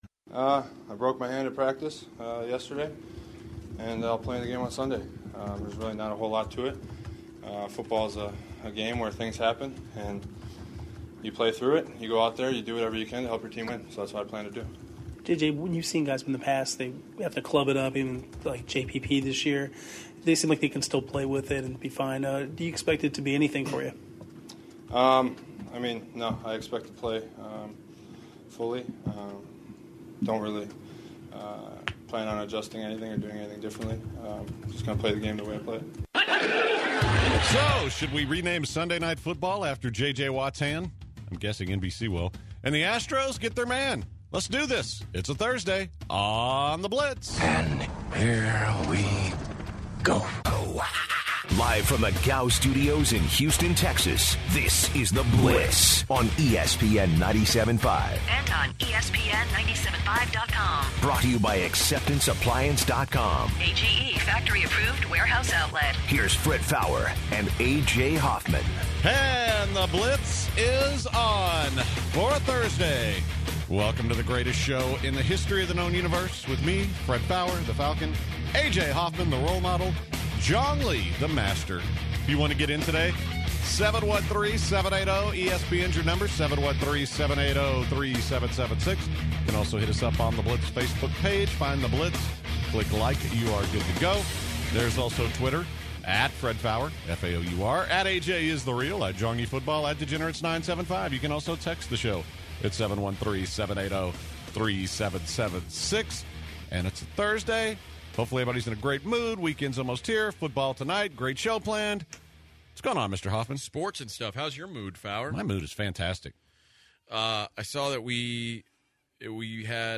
Some listeners called in to talk about their stories of drinking Four Loko. The Astros have a traded for a new closer.